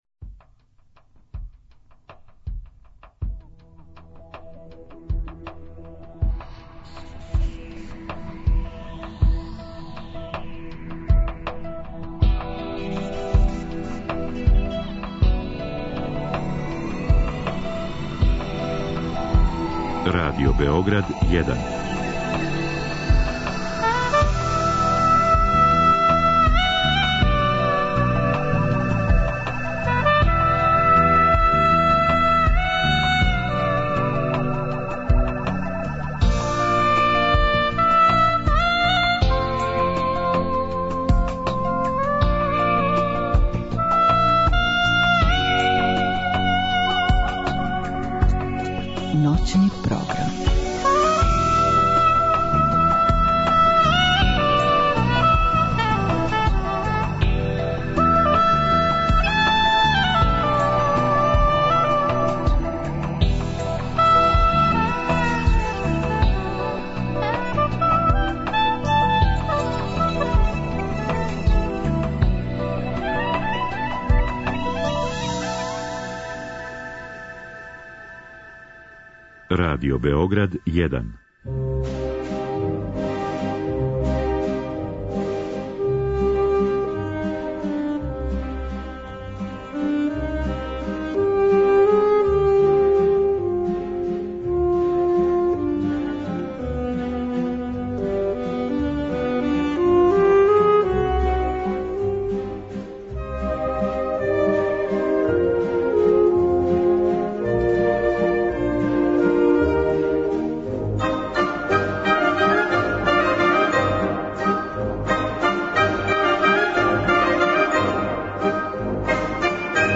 У термину од 02-04 слушаћете одабране композиције руских и српских аутора.